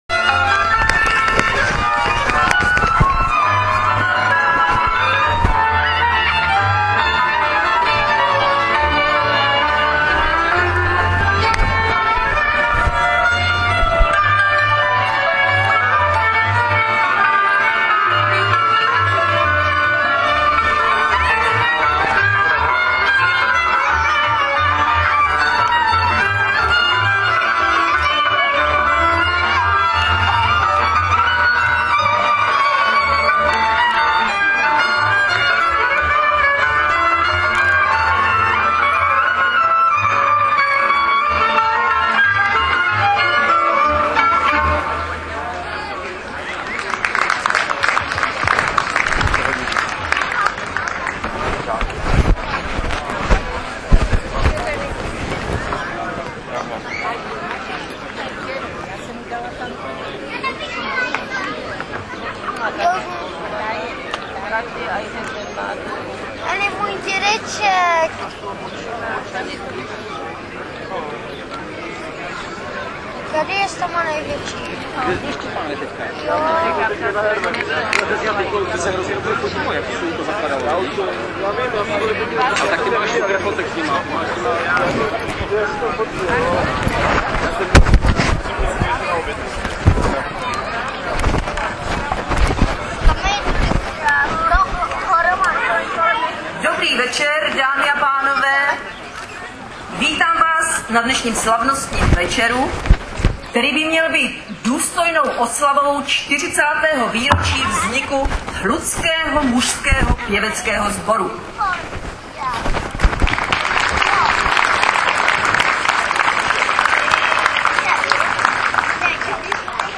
Fotky jsou ze sobotního podvečera a večera (bohužel samotné zpívání bylo už za tmy, takže fotky bída), kdy Mužský sbor Hluk slavil čtyřicet let.